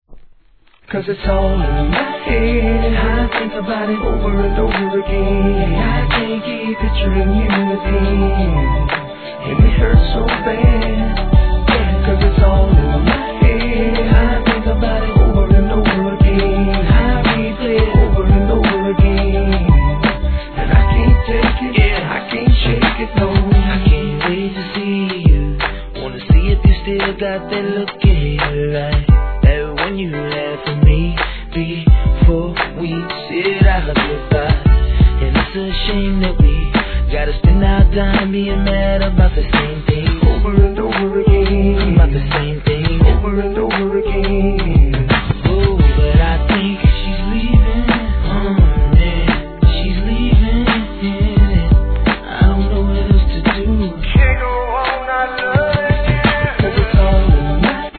HIP HOP/R&B
穏やかなメロディー・ライン が心地よい売れ線!